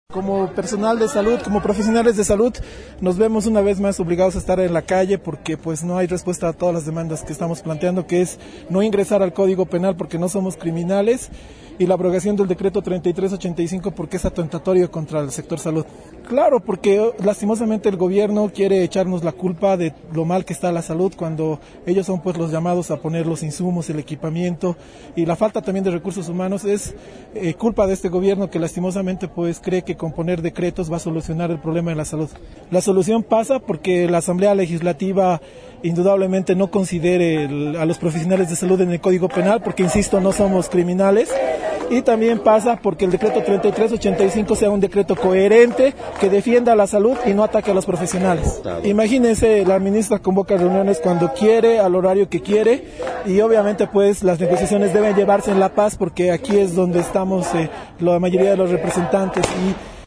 Declaración de galenos.